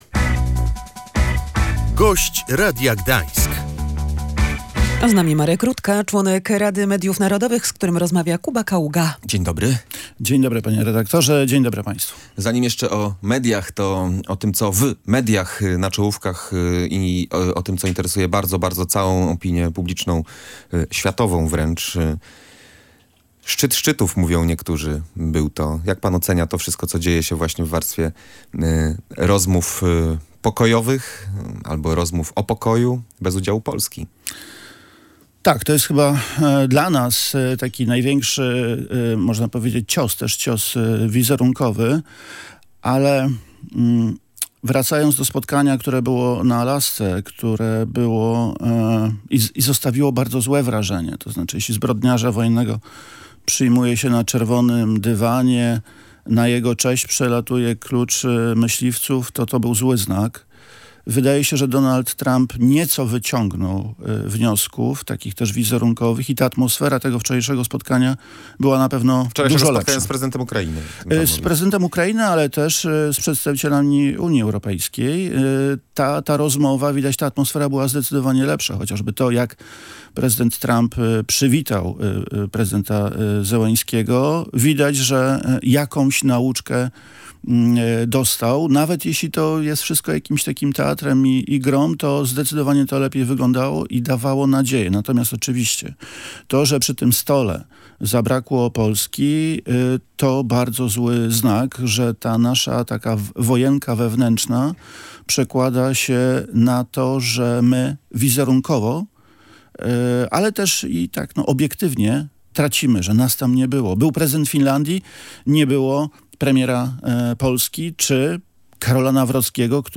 We wrześniu lub październiku powinien być gotowy projekt nowej ustawy medialnej – zapowiedział w Radiu Gdańsk Marek Rutka, członek Rady Mediów Narodowych.